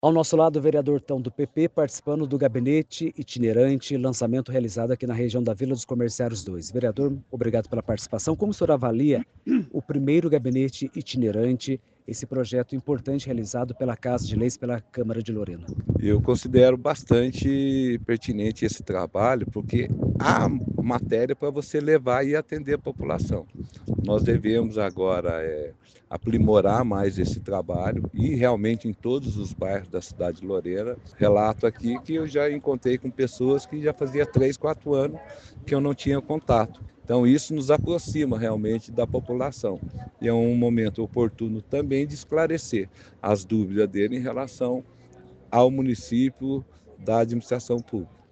Áudio do vereador Waldemilson da Silva (Tão – PP);